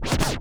scratch12.wav